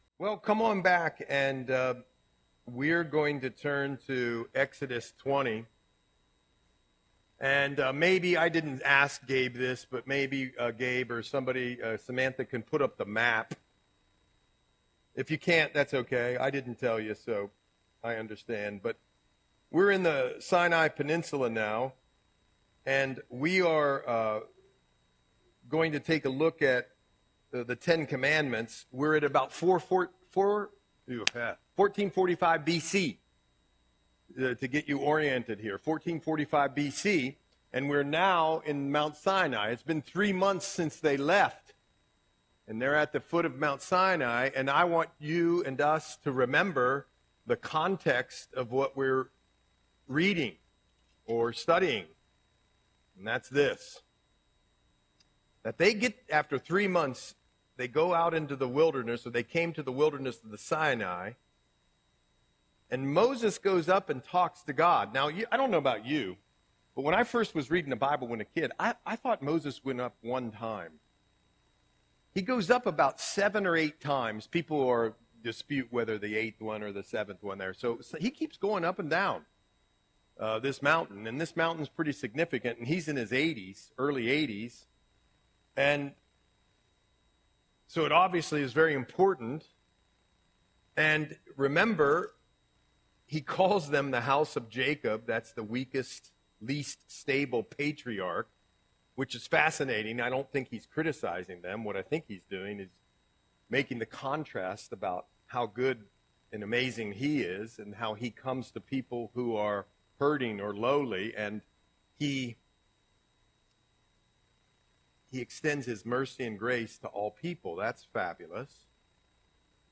Audio Sermon - March 5, 2025